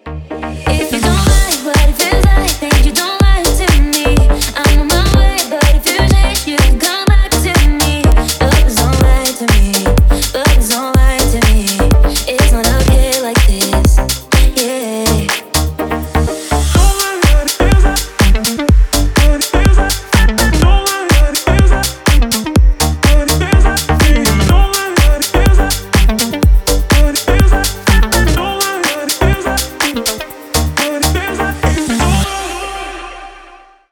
Танцевальные
ритмичные